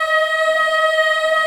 Index of /90_sSampleCDs/USB Soundscan vol.28 - Choir Acoustic & Synth [AKAI] 1CD/Partition A/04-CHILD EHF
D#4 CHI EH-R.wav